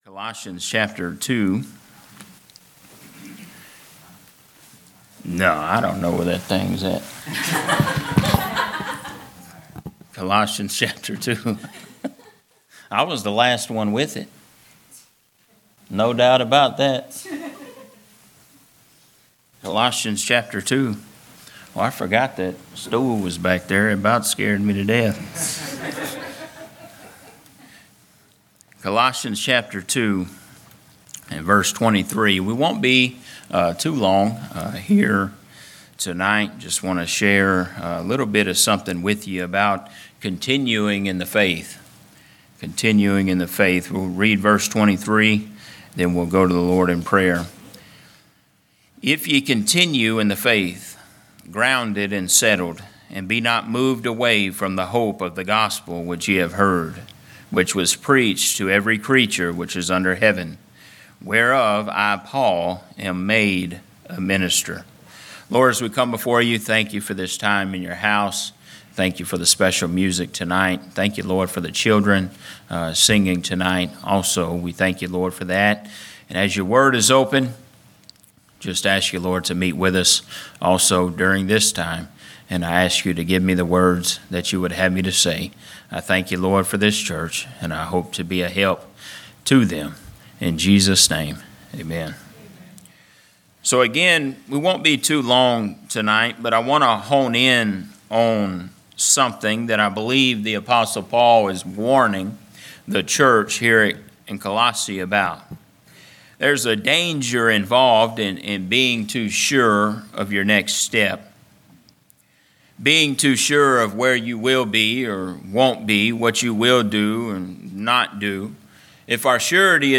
Sermons not part of a specific series